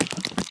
Minecraft Version Minecraft Version snapshot Latest Release | Latest Snapshot snapshot / assets / minecraft / sounds / mob / spider / step3.ogg Compare With Compare With Latest Release | Latest Snapshot
step3.ogg